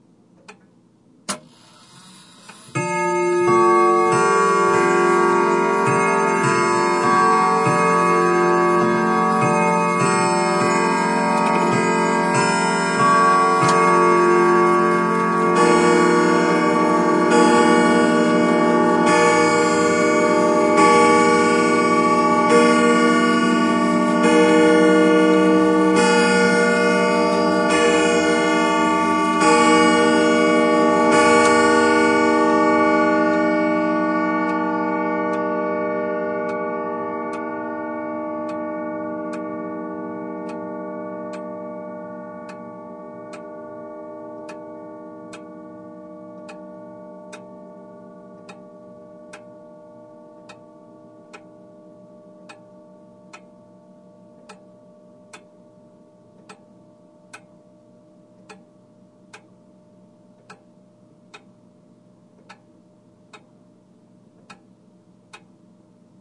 祖父钟 " 祖父钟报时 10
描述：一个祖父钟敲响了十点的钟声。 由于这是在钟内录制的，所以你还能听到钟的机制的所有其他环境噪音，包括滴答的声音。
Tag: 迷人 古董 时钟 拟音 祖父 机械 罢工 滴答 钟声 钟声